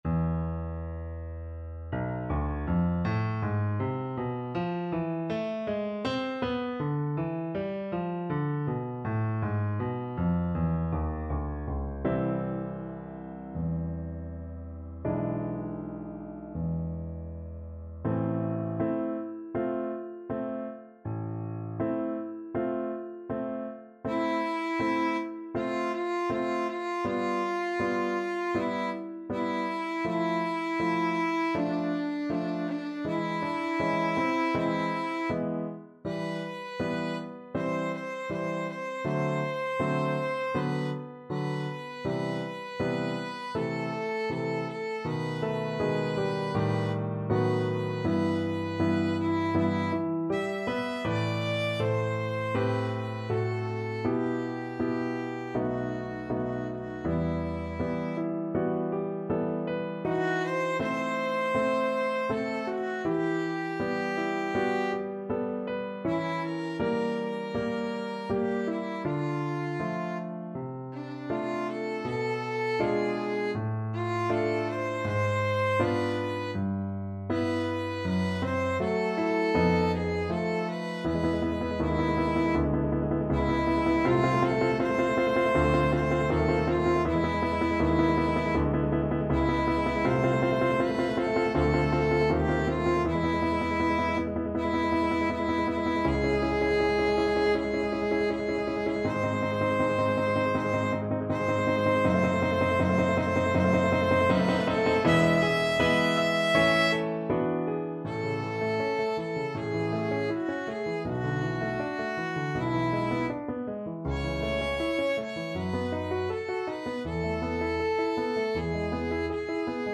~ = 100 Molto moderato =80
Violin  (View more Easy Violin Music)
Classical (View more Classical Violin Music)